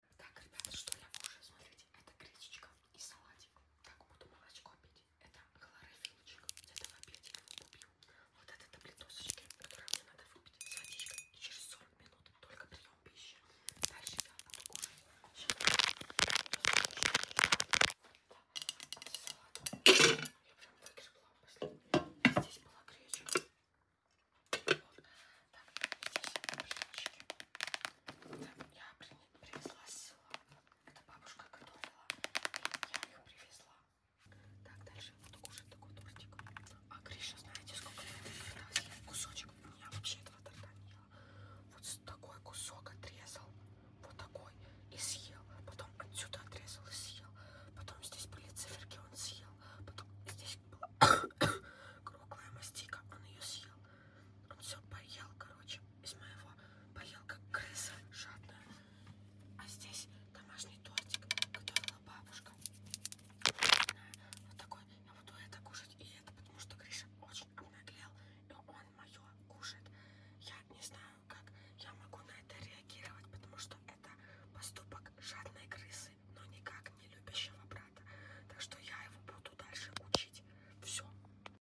Асмр что я кушаю